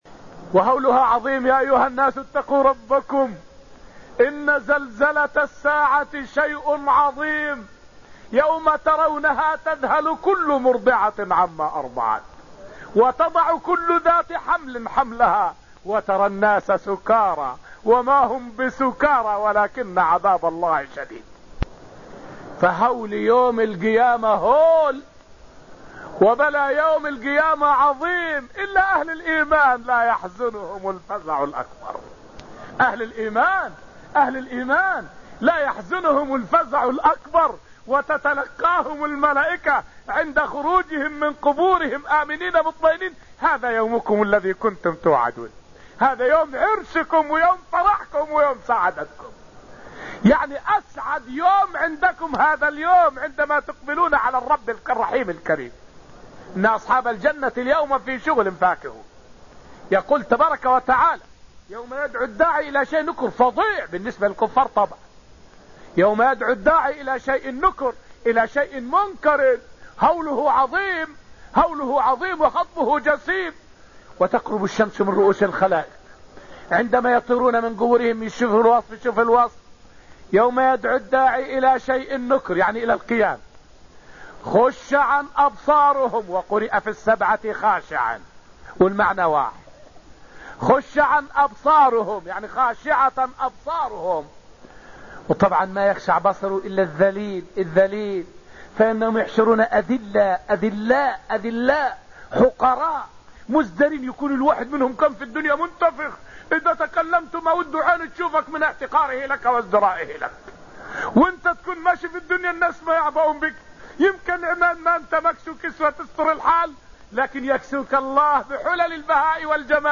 فائدة من الدرس الثاني من دروس تفسير سورة القمر والتي ألقيت في المسجد النبوي الشريف حول أنه من علامات الساعة عقوق الوالدين.